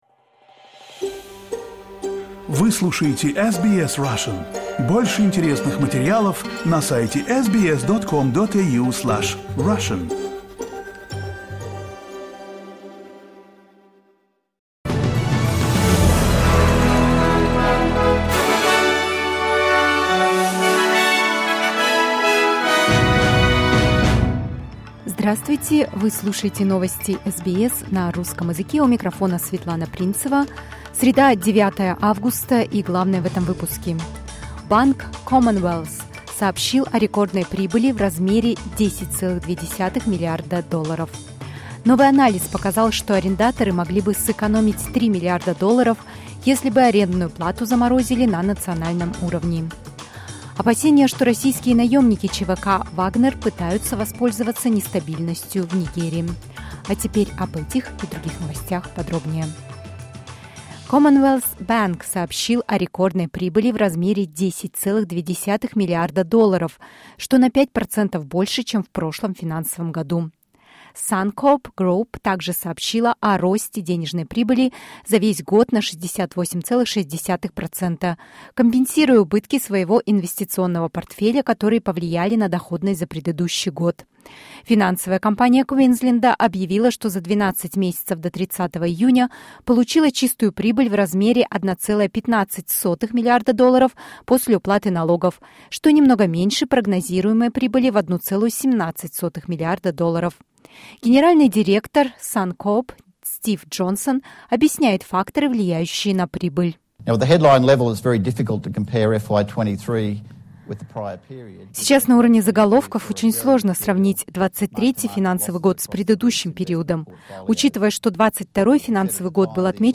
SBS news in Russian — 09.08.2023